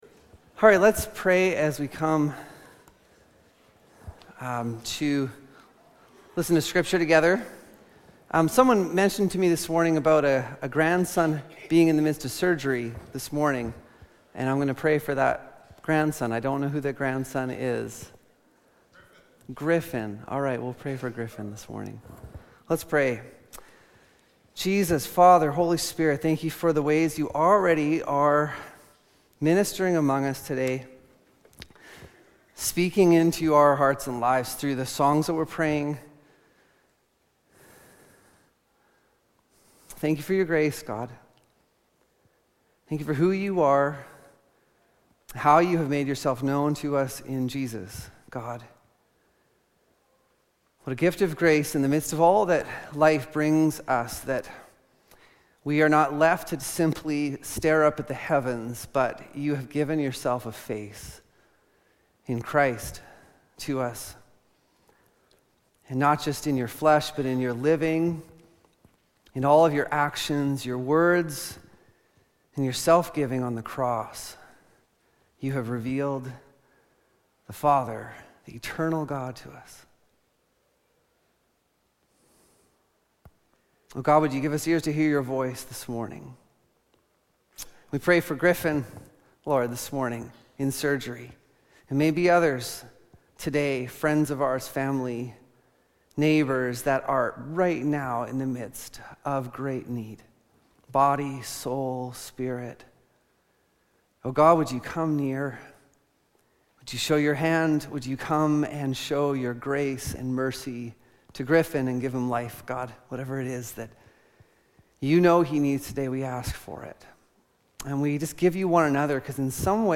Lambrick Sermons | Lambrick Park Church